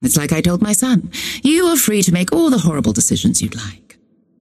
Calico voice line - It's like I told my son, you are free to make all the horrible decisions you'd like.